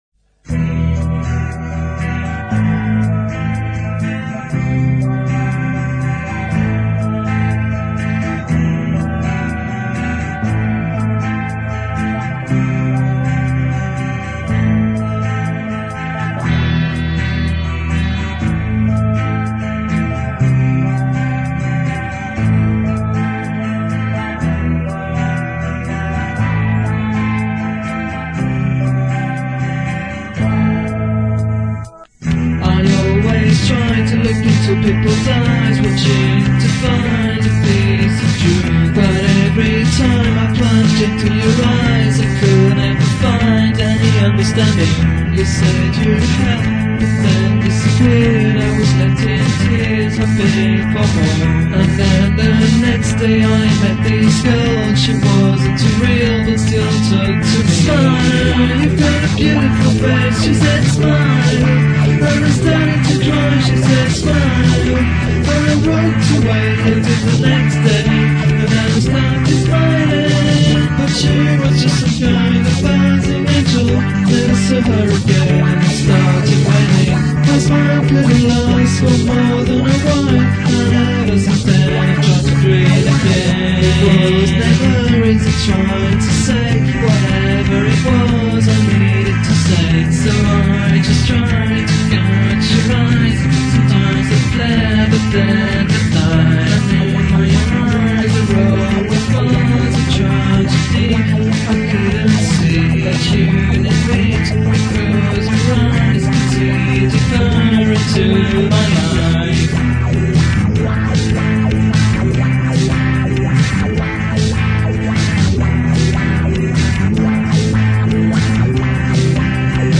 OK, let's be fair, I think the singing is atrocious, the music mostly rubbish, and the lyrics cringeworthy.